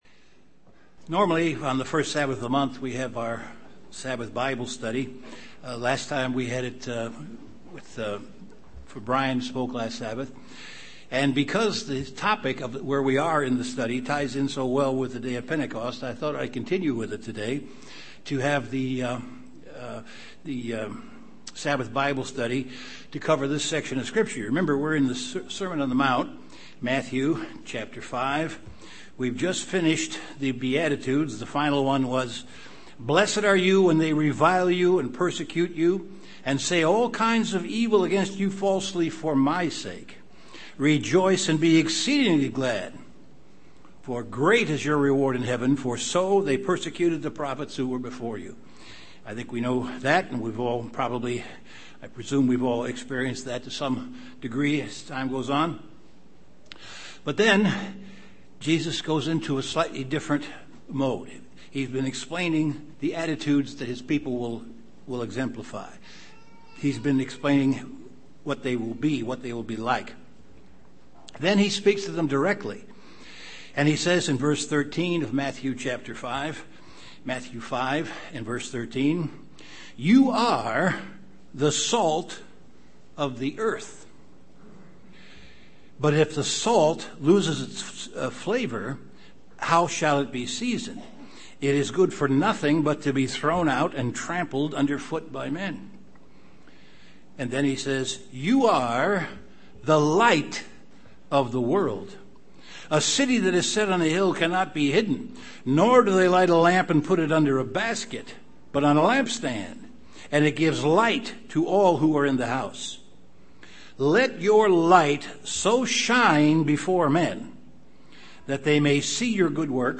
The third session of an in-depth Bible Study on the Sermon on the Mount. This study discusses salt and light
Given in Chicago, IL Beloit, WI